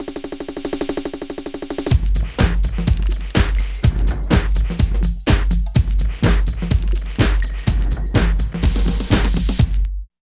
81 LOOP   -L.wav